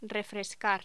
Locución: Refrescar
voz
Sonidos: Voz humana